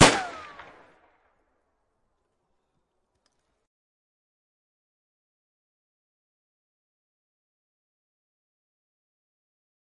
手枪射击跳弹
重新编辑并结合两个来源。
标签： 手枪 砰的一声 跳弹 射击 一声枪响
声道立体声